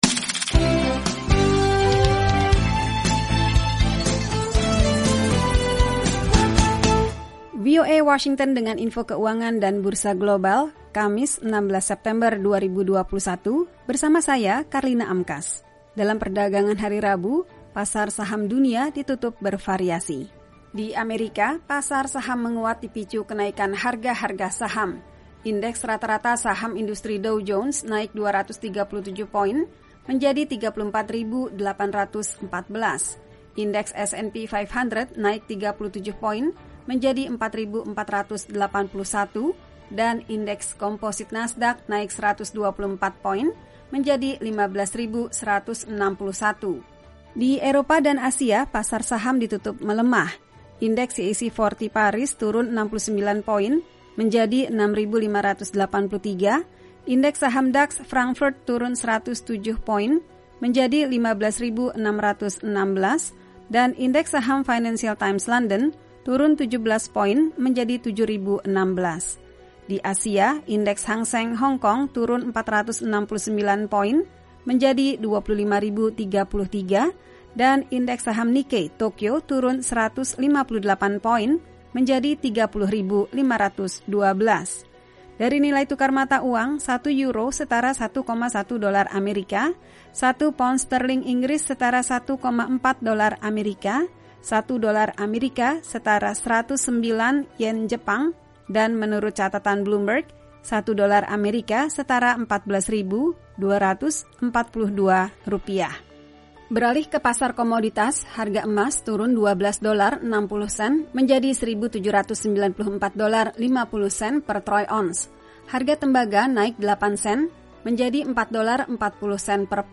Info Ekonomi